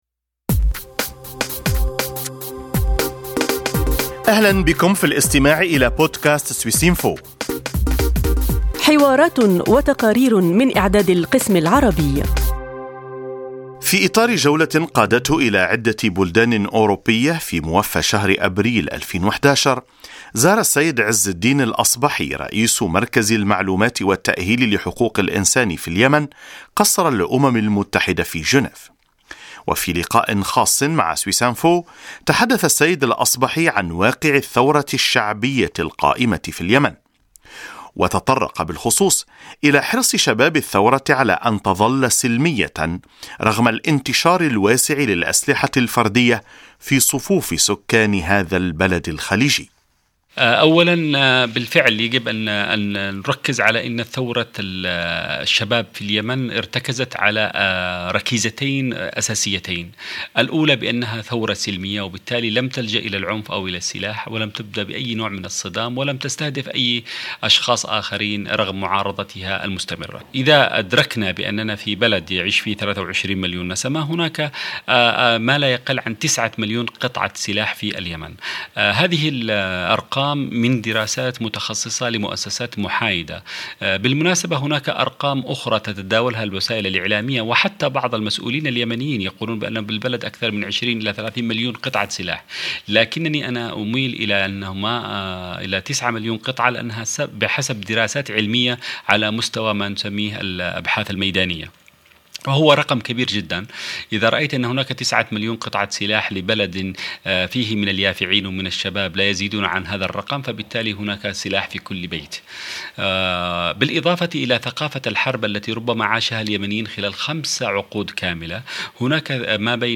في حوار خاص، يشرح عز الدين الأصبحي، الناشط الحقوقي اليمني واقع الثورة الشعبية المستمرة في بلاده ويشدد على طابعها السلمي والمدني.